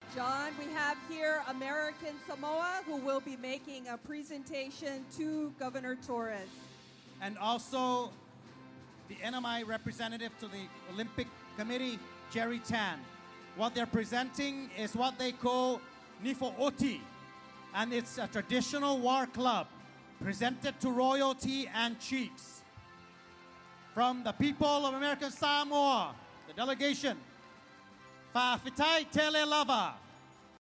American Samoa’s team at the Pacific Mini Games in Saipan, Northern Marianas, presented gifts during the parade of nations at the Games opening ceremony yesterday.
Here’s what the emcees announced during the presentation.